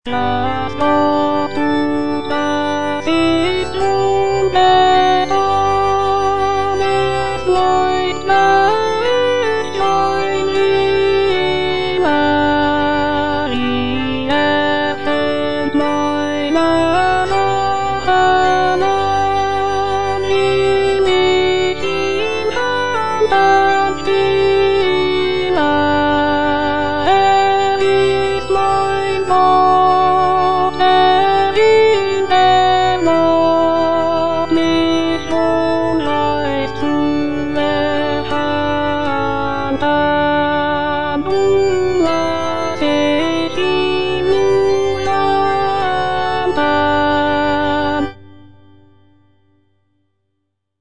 Choralplayer playing Cantata
Alto (Voice with metronome) Ads stop